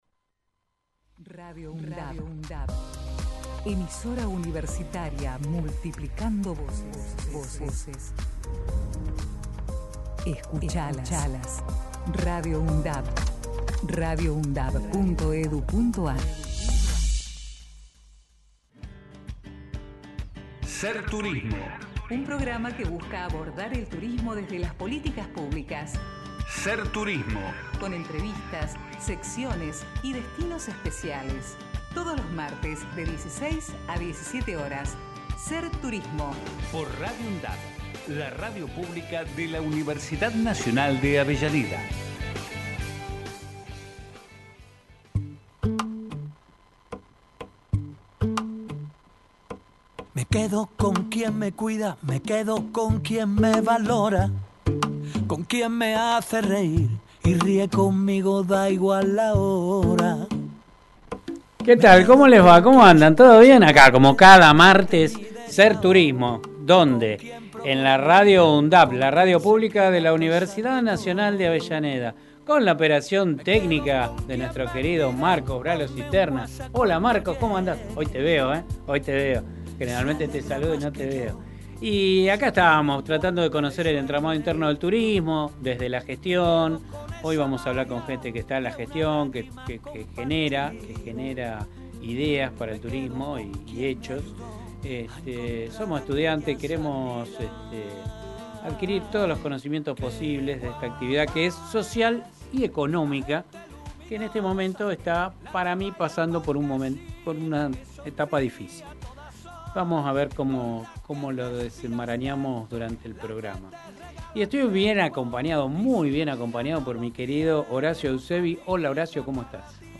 Con entrevistas, secciones y destinos especiales, todos los martes de 16 a 17 horas Integrantes